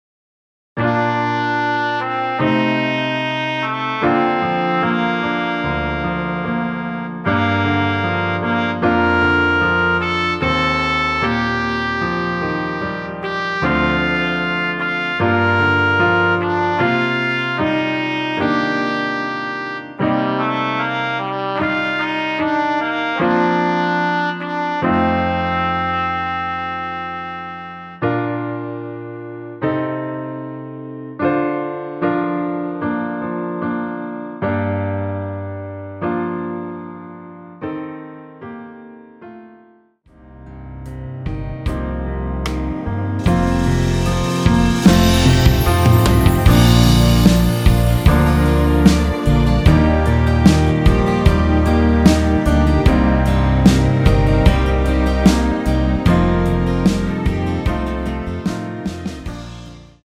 원키에서(-2)내린 MR입니다.
Bb
앞부분30초, 뒷부분30초씩 편집해서 올려 드리고 있습니다.
중간에 음이 끈어지고 다시 나오는 이유는